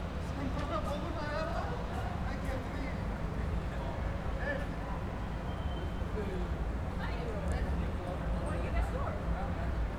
Environmental
Streetsounds
Noisepollution